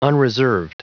Prononciation du mot unreserved en anglais (fichier audio)
Prononciation du mot : unreserved